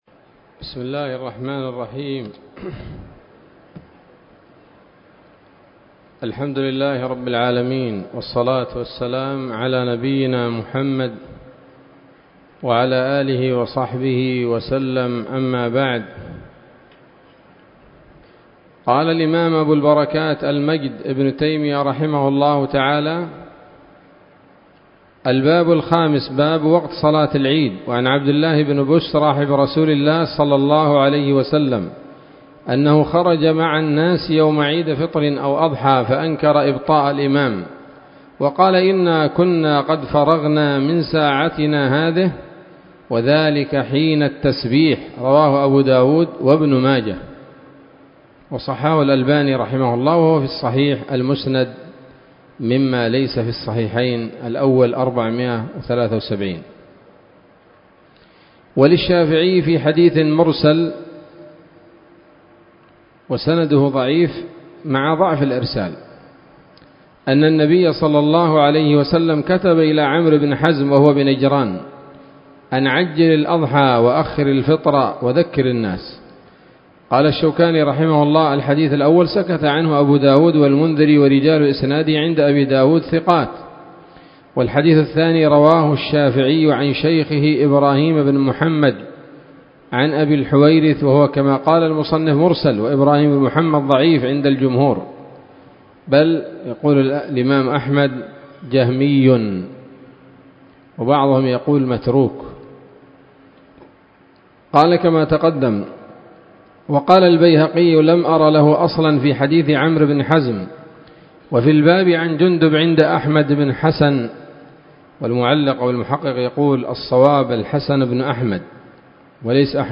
الدرس السابع من ‌‌‌‌كتاب العيدين من نيل الأوطار